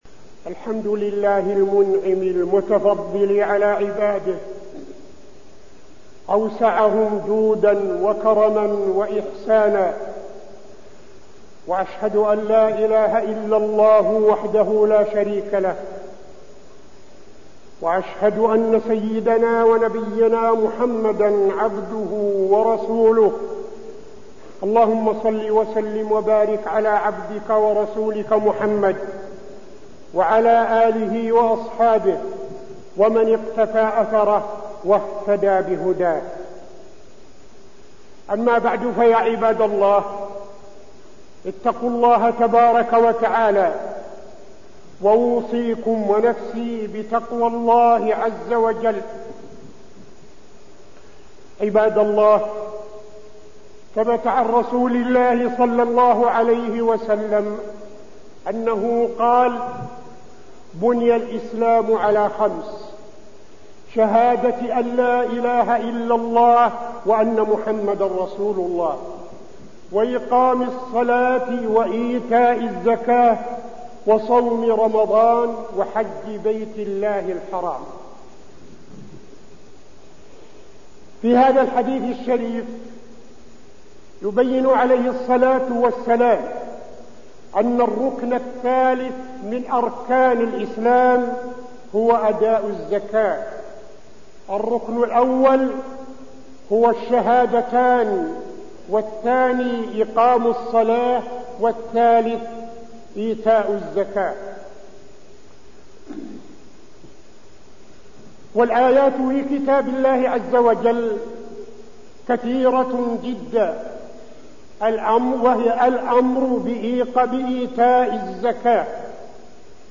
تاريخ النشر ٢٠ شعبان ١٤٠٥ هـ المكان: المسجد النبوي الشيخ: فضيلة الشيخ عبدالعزيز بن صالح فضيلة الشيخ عبدالعزيز بن صالح الزكاة